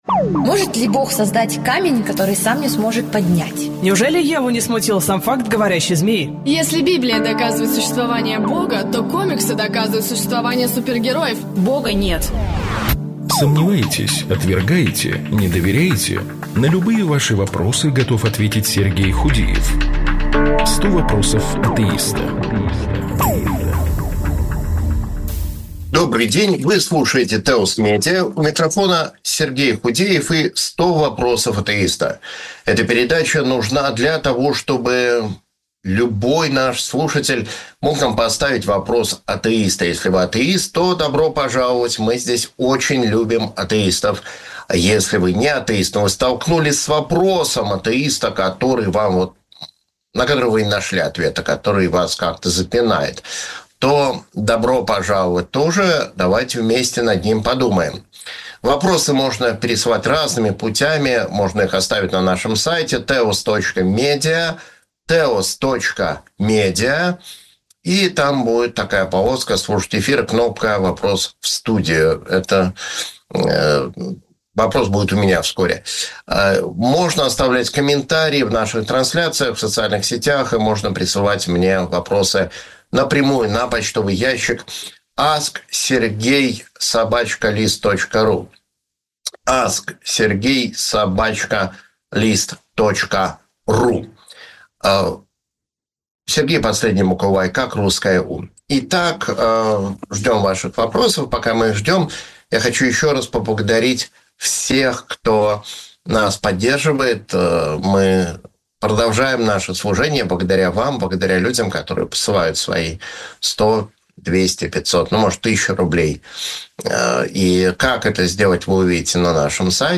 В прямом эфире